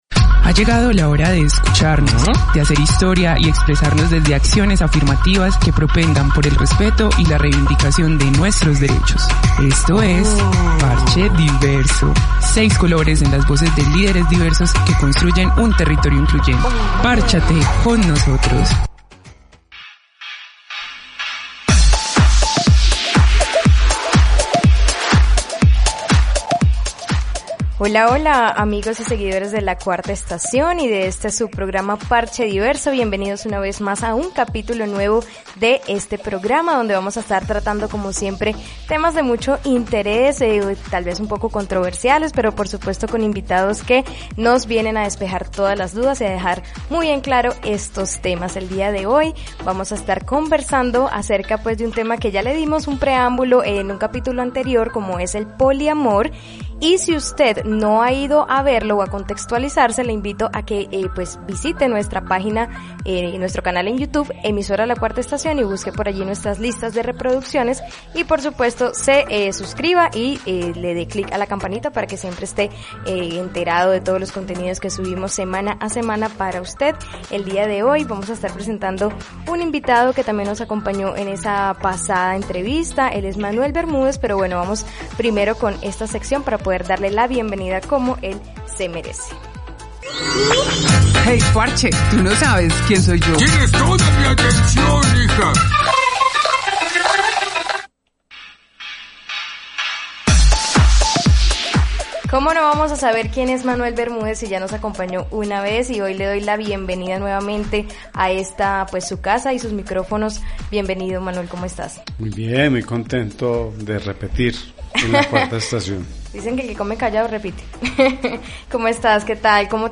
quién nos cuenta sus experiencias a través de esta entrevista